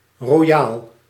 Ääntäminen
IPA : /ˈdʒɛn(ə)ɹəs/